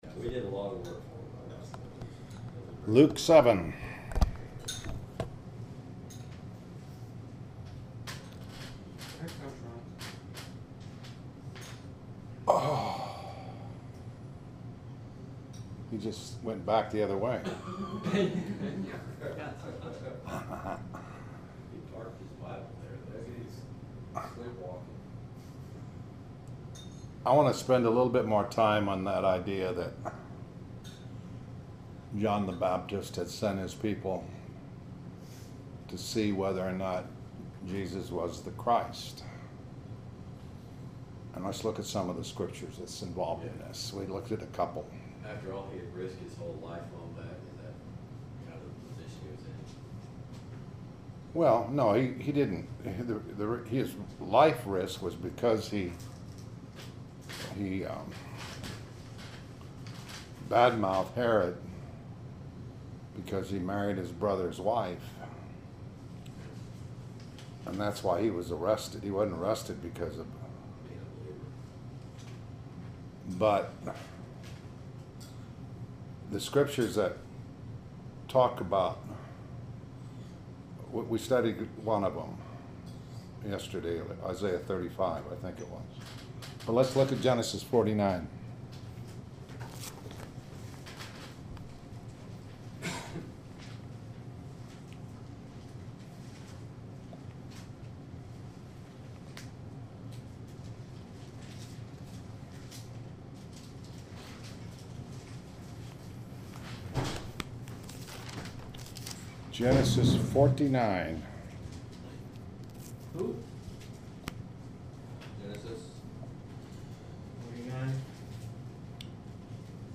Morning Bible Studies